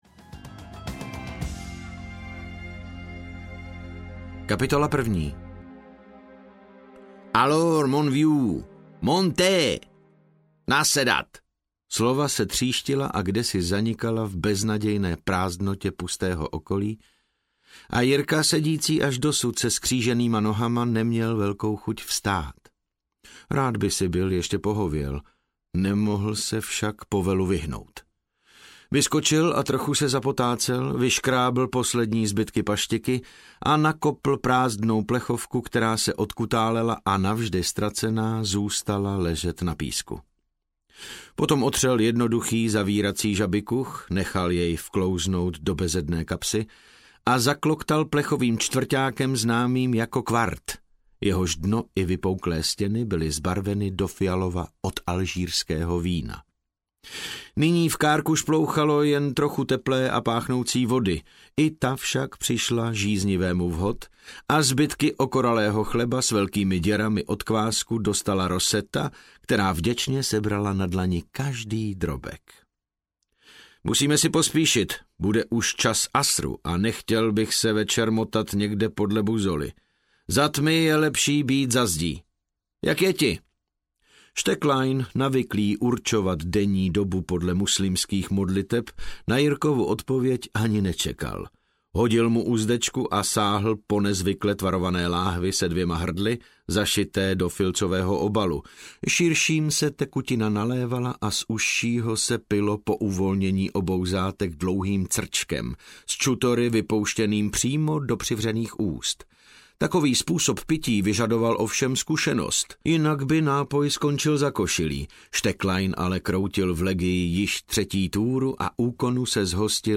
Pevnost v poušti audiokniha
Ukázka z knihy
• InterpretDavid Novotný